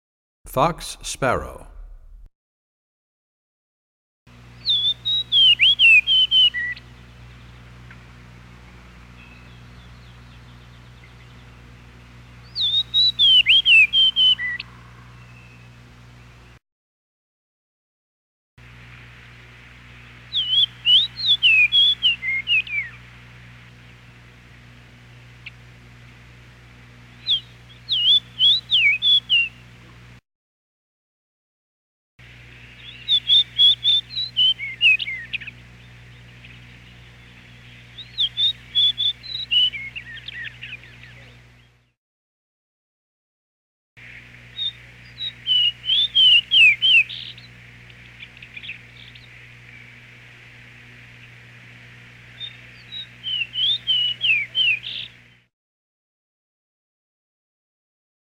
37 Fox Sparrow.mp3